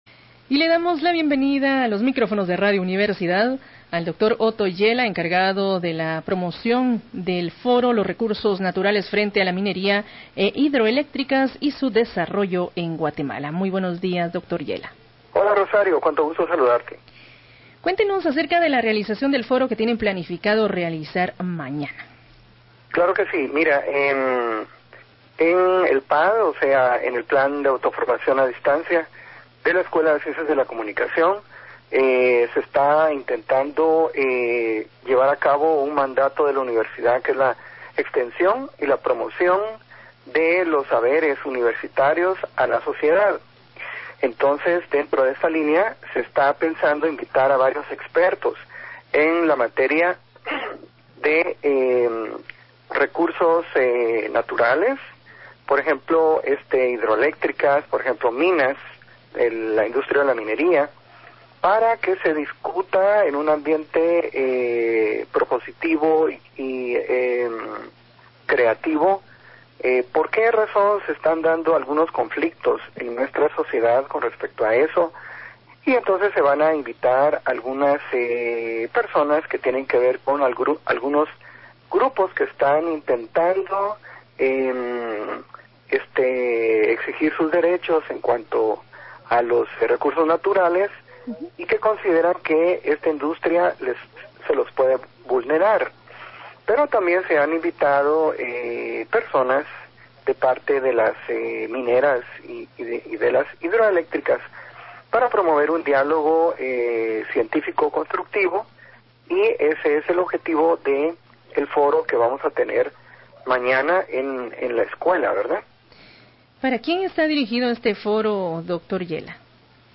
EL INFORMATIVO / RADIO UNIVERSIDAD: Entrevista